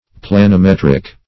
Search Result for " planimetric" : The Collaborative International Dictionary of English v.0.48: Planimetric \Plan`i*met"ric\ (?; 277), Planimetrical \Plan`i*met"ric*al\, a. [Cf. F. planim['e]trique.]